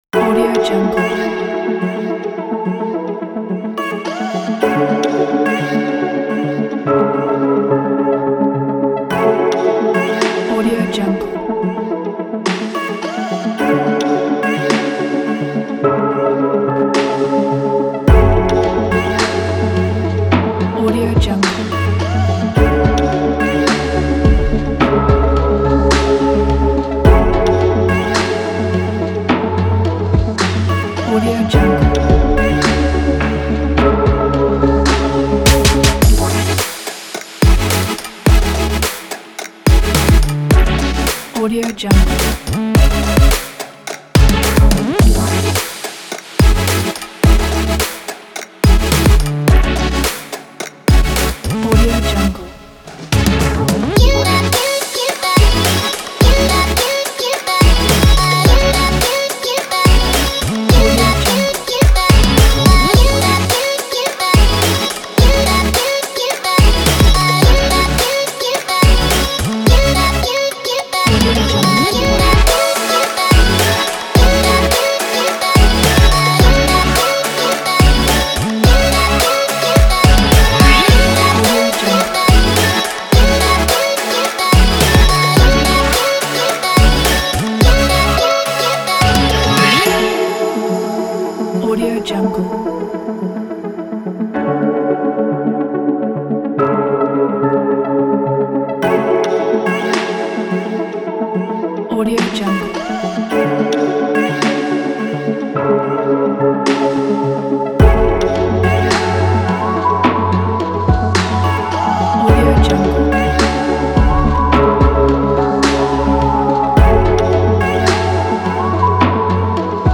الکترونیک